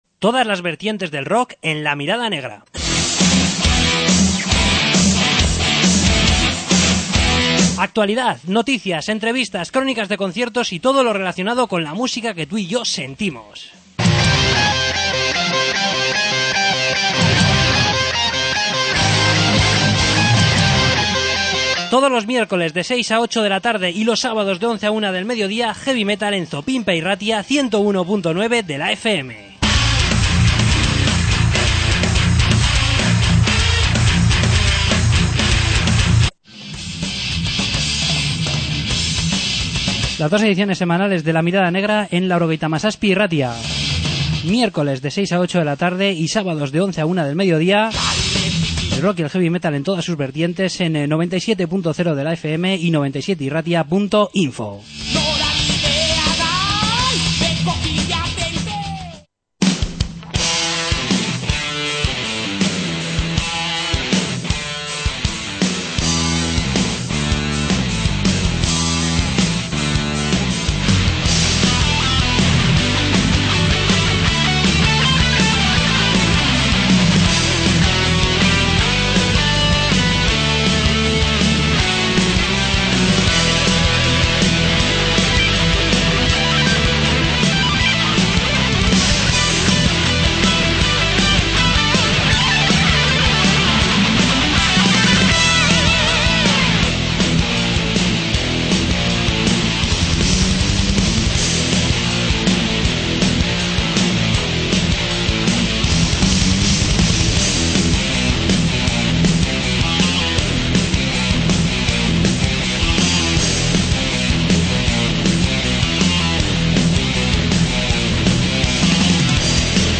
Entrevista con Helsinki 101